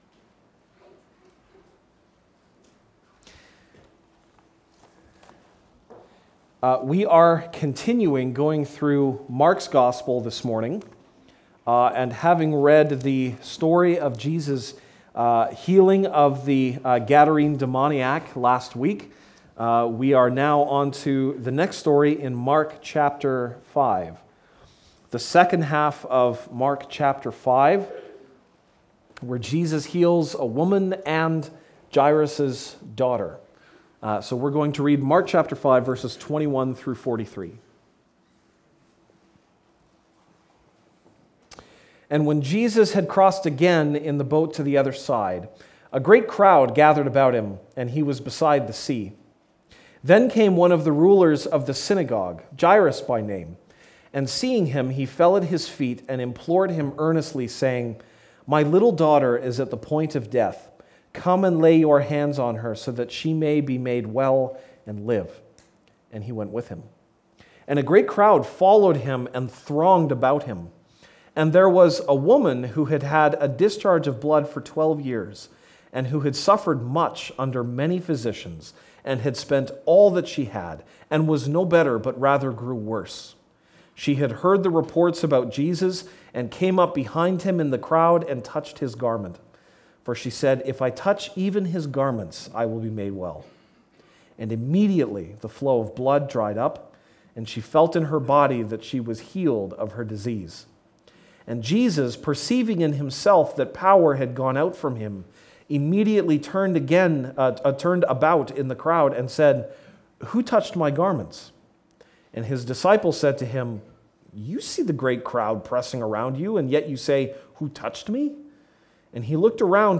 January 31, 2016 (Sunday Morning)